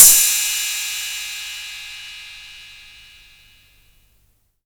• Cymbal Sound Sample D Key 09.wav
Royality free cymbal drum sample tuned to the D note. Loudest frequency: 8270Hz
cymbal-sound-sample-d-key-09-JVb.wav